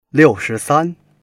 liu4shi2san1.mp3